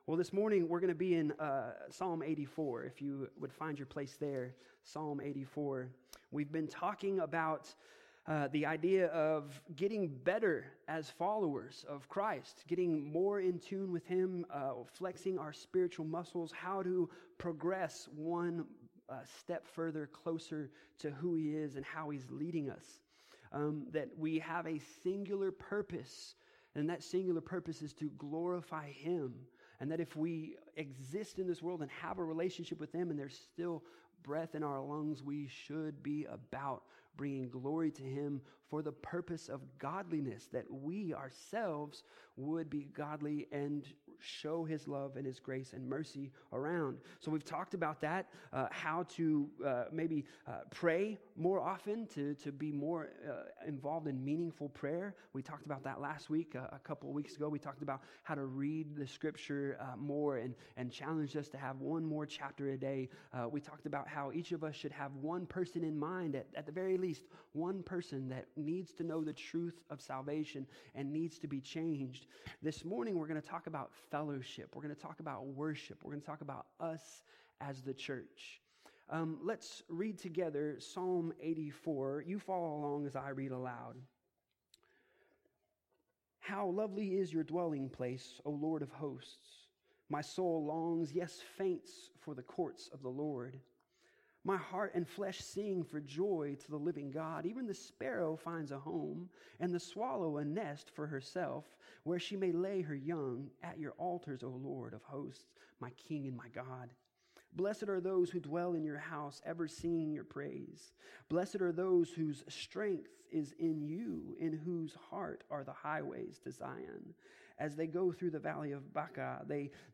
The audio recording is also from the in-person gathering – recorded live through our church sound system.